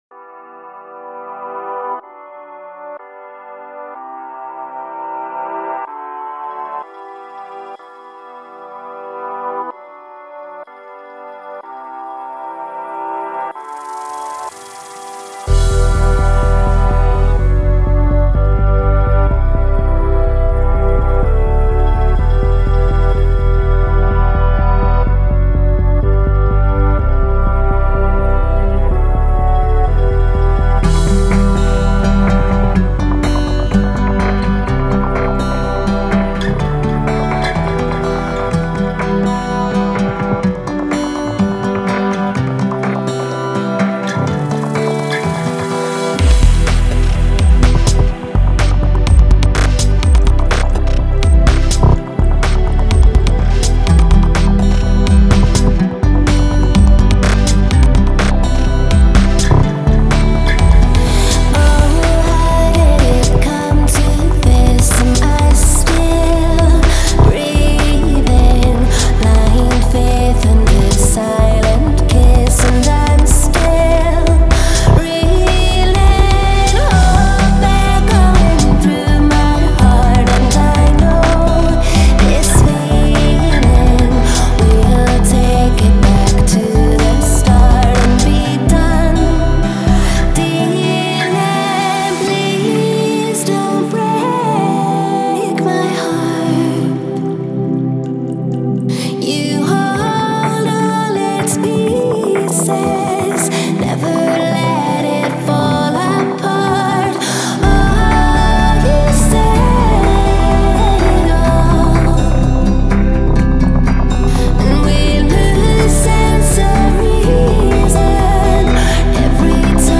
Хорошая баллада, что то кельтское там звучит